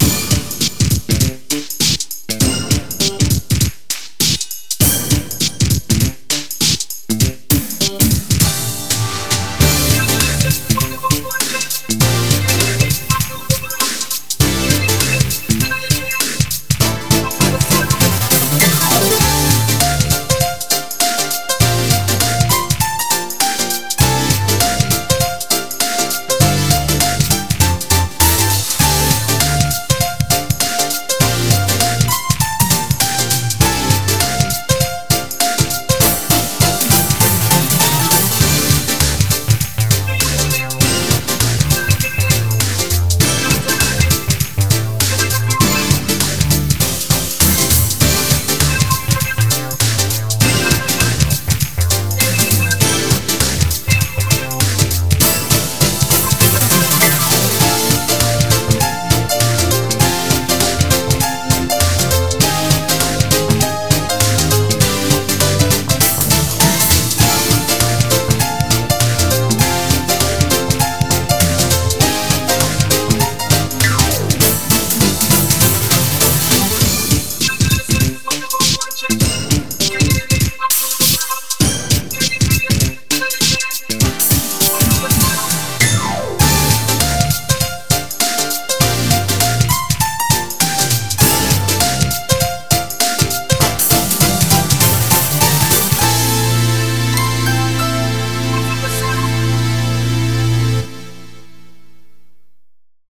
BPM100
Better quality audio.